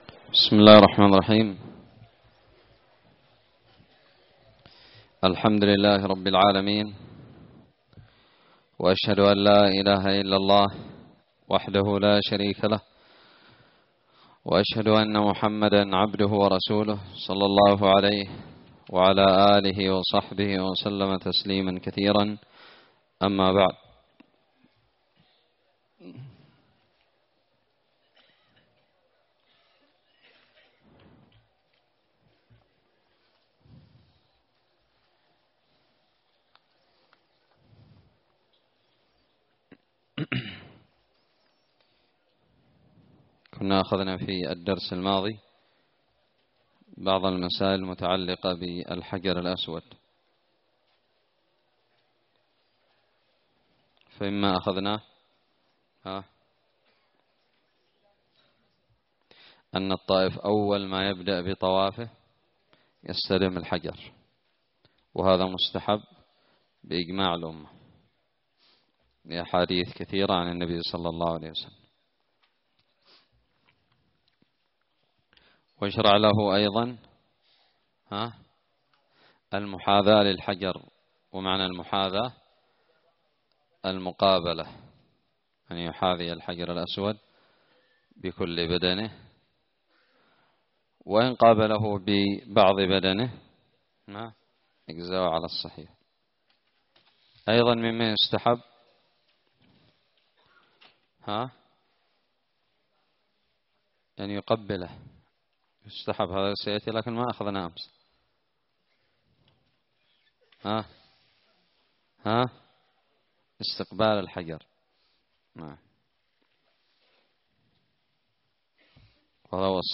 الدرس الحادي والأربعون من كتاب الحج من الدراري
ألقيت بدار الحديث السلفية للعلوم الشرعية بالضالع